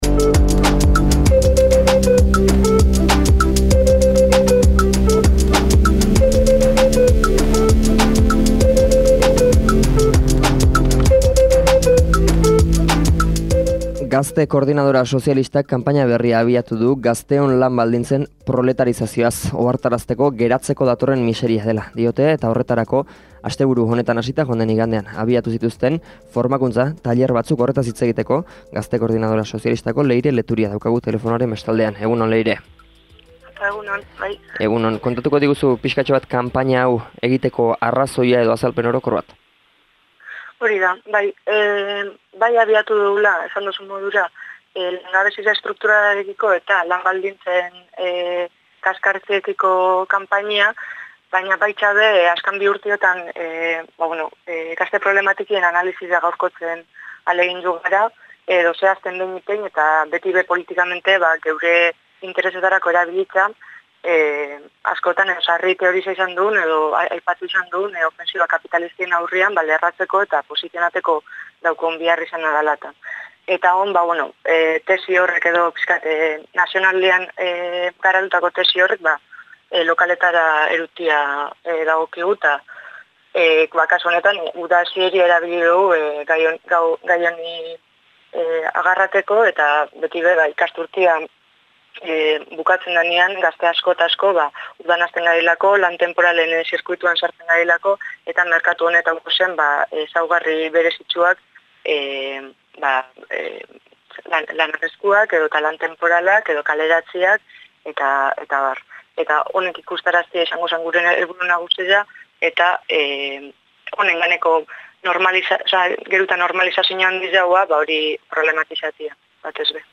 Elkarrizketa osoa hemen entzungai.